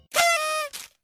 Corneta de papel